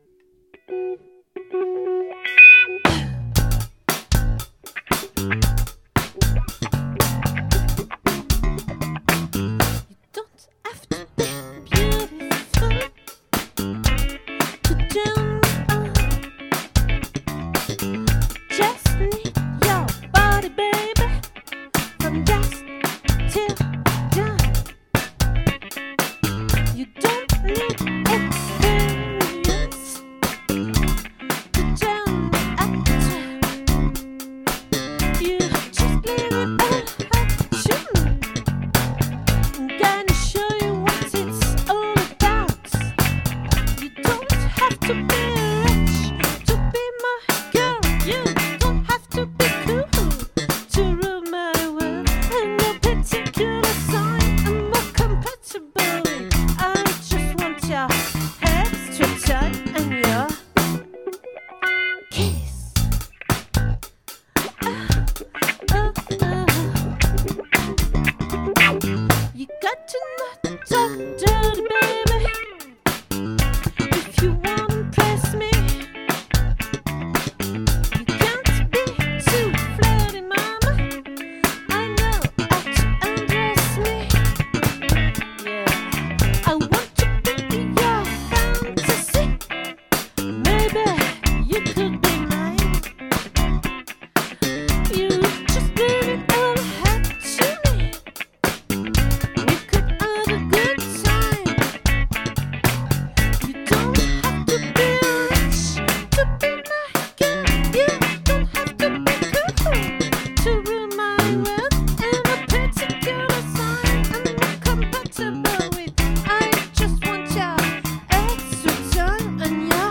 🏠 Accueil Repetitions Records_2022_03_16